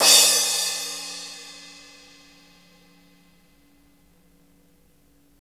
Index of /90_sSampleCDs/Roland - Rhythm Section/CYM_Crashes 1/CYM_Crash menu
CYM CRASH01R.wav